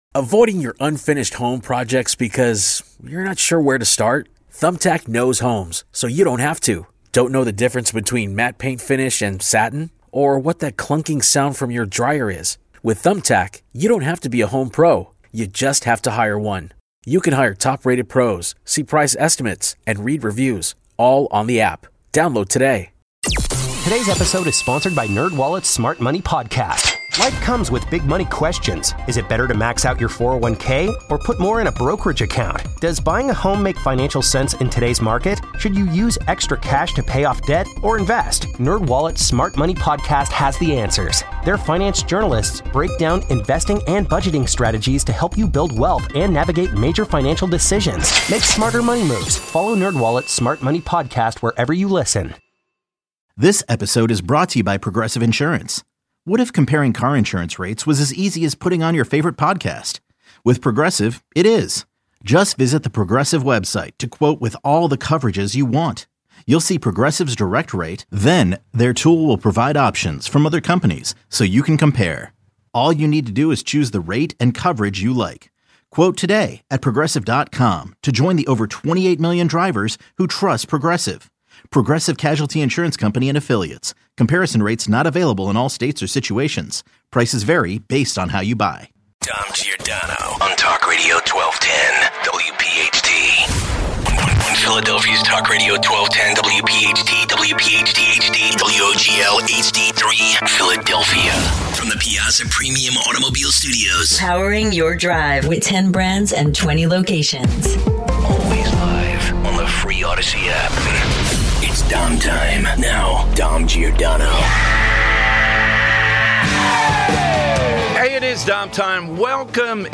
How does it feel that Mom4Librty has a proverbial target on their backs? 1250 - Your calls to wrap up the hour.